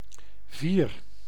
Ääntäminen
IPA: /katʁ/